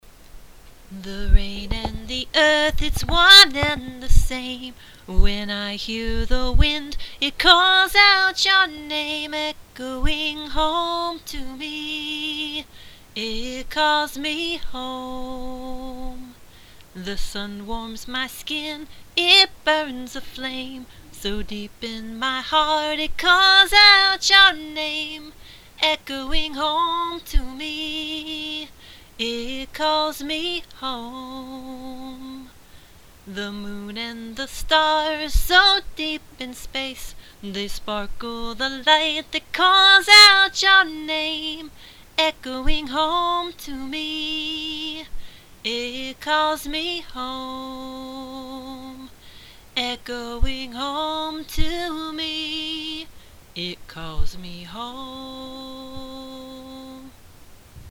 Unfinished song/a cappella